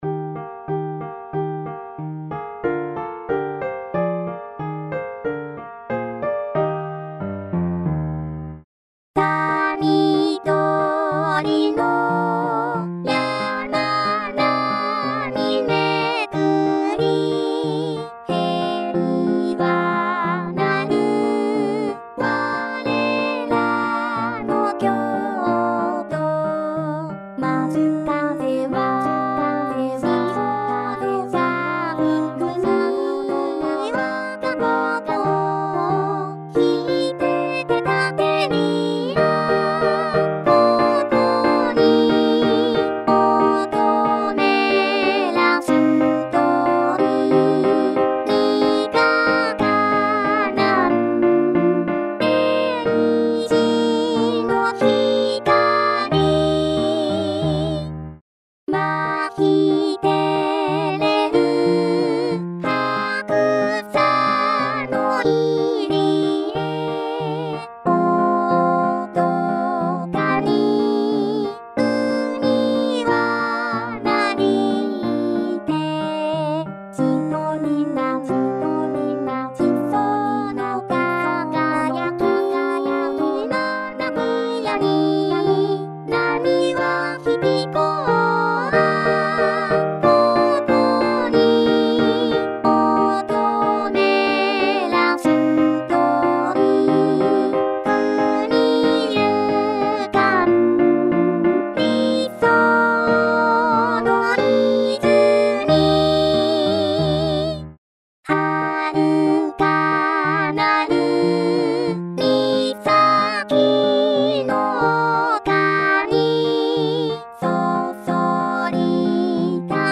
onjuku_koukou_kouka.mp3